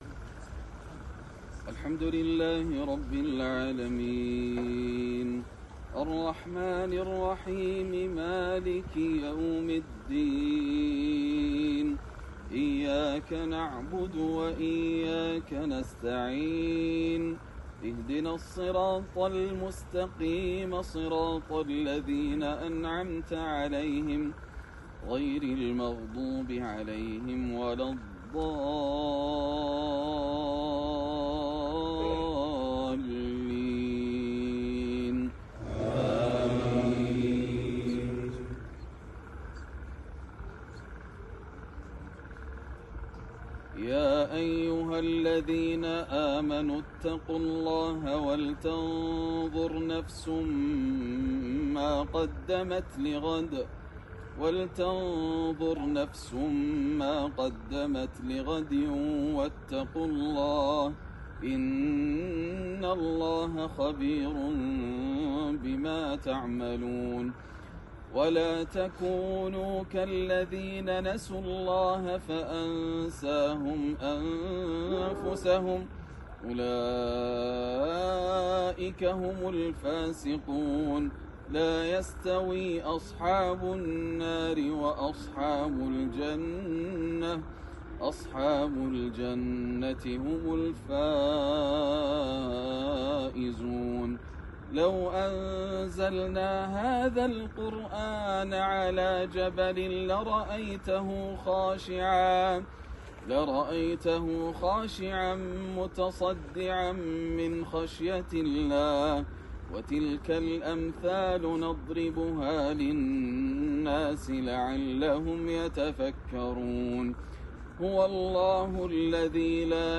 تلاوة حَصرية بلا مكبرات صوتية للشيخ ياسر الدوسري في إحدى المناسبات خلال زيارته جنوب أفريقيا ١٤٤٦ > زيارة الشيخ ياسر الدوسري لدولة جنوب أفريقيا > مزامير الفرقان > المزيد - تلاوات الحرمين